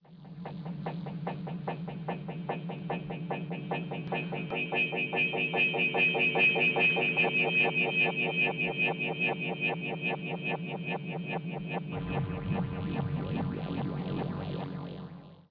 The lights and sound as Holly is transported on her journey are very similar to those seen and heard when Builder arrives in the Temple in "Musician".